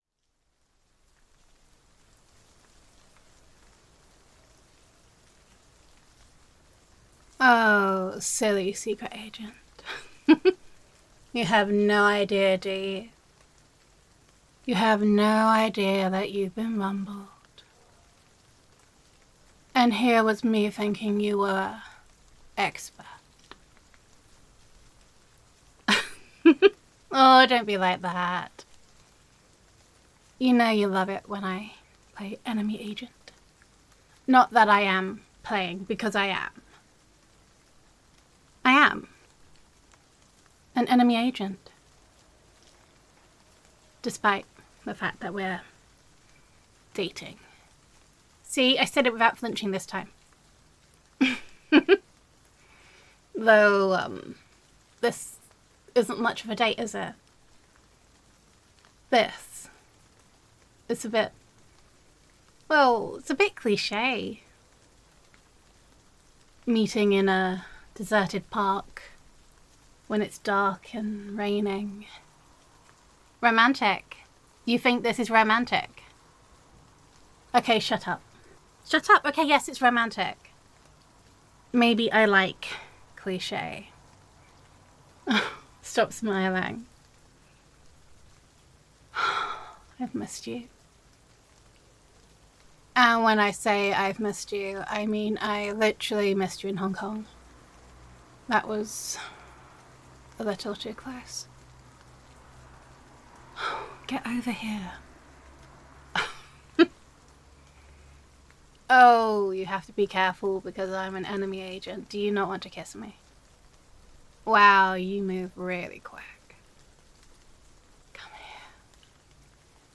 [F4A]
[Secret Agent Roleplay]